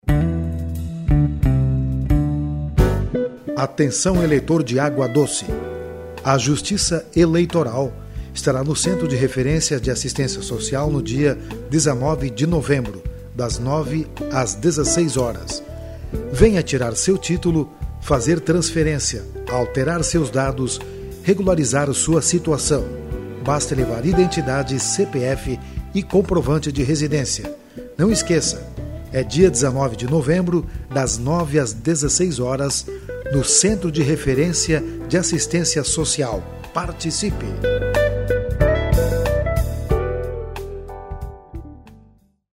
spot_agua_doce_19-11.mp3